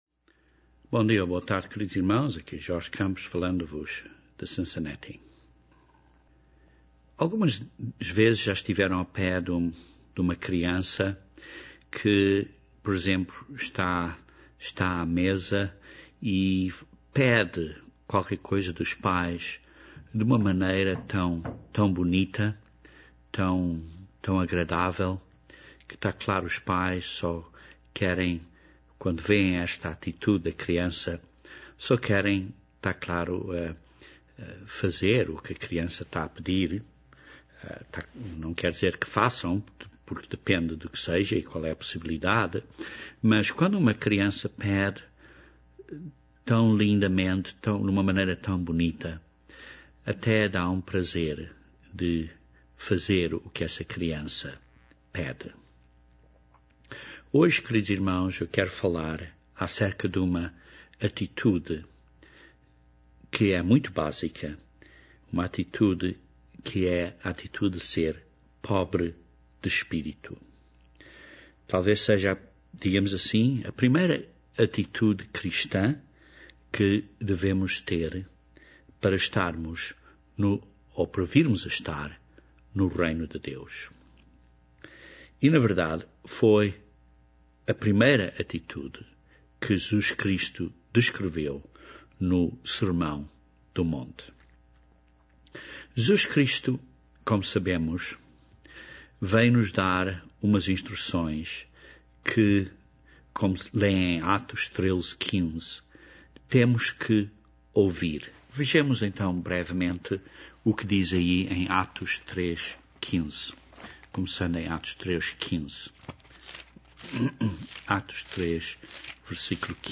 Este sermão começa uma série de sermões acerca dos ensinamentos de Jesus Cristo. Este sermão descreve a primeira bela atitude cristã que Jesus Cristo menciona no sermão do monte, a atitude de sermos 'pobres de espírito'.